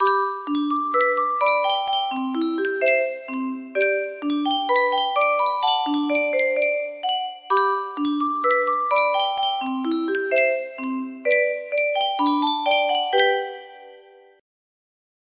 Yunsheng Music Box - What a Friend We Have in Jesus Y225
Full range 60